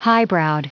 Prononciation du mot highbrowed en anglais (fichier audio)
Prononciation du mot : highbrowed